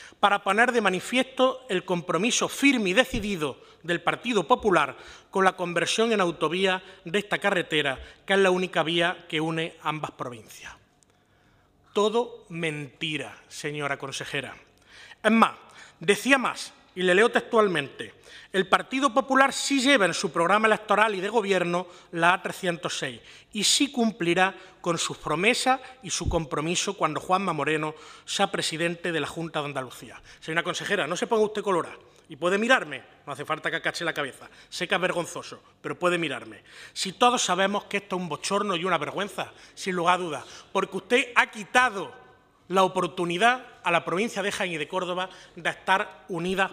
Comisión de Fomento
Cortes de sonido